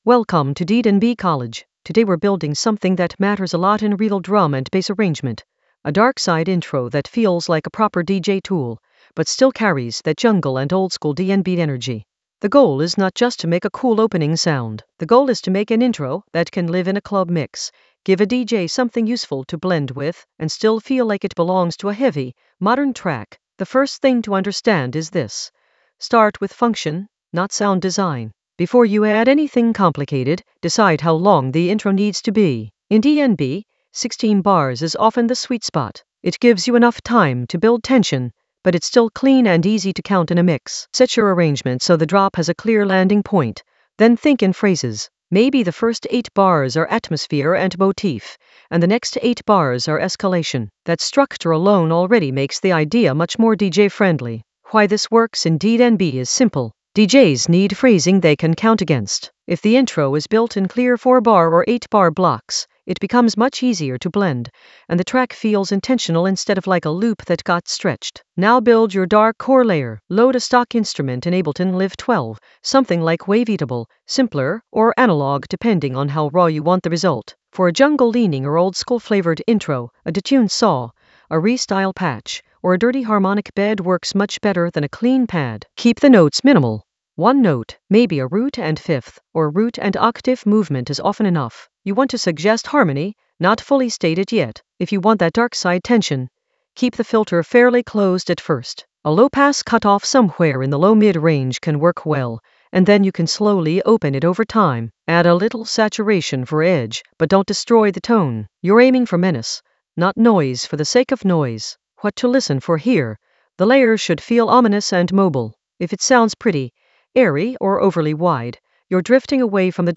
An AI-generated advanced Ableton lesson focused on Modulate a darkside intro with DJ-friendly structure in Ableton Live 12 for jungle oldskool DnB vibes in the Workflow area of drum and bass production.
Narrated lesson audio
The voice track includes the tutorial plus extra teacher commentary.